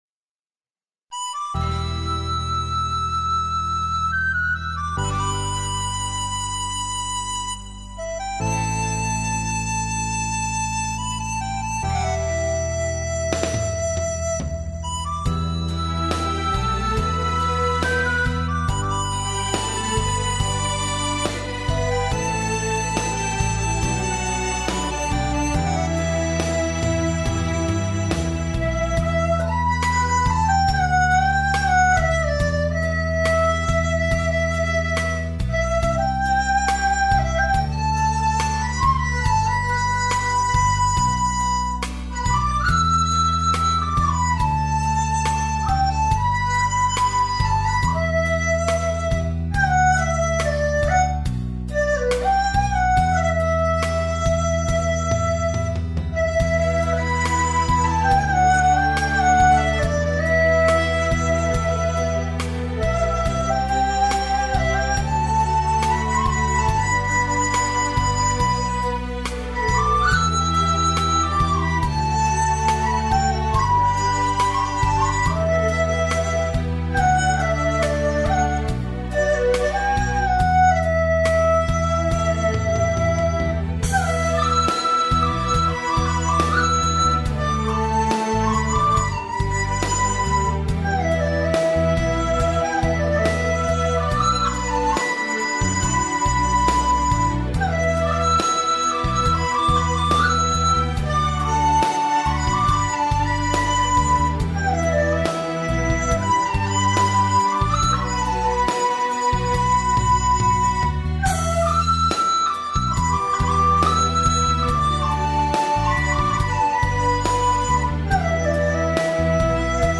老歌新吹，赶个末班车吧：））祝福大家新春快乐，吉祥如意！
经典好曲，旋律优美，笛声悠扬
很适合笛声演奏。